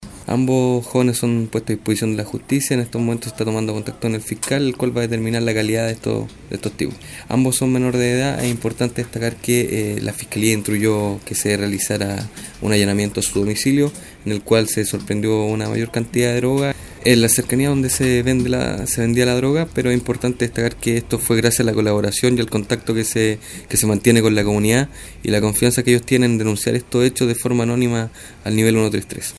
El oficial de Carabineros indicó que en posteriores allanamientos al domicilio de uno de ellos se encontró más de esta droga.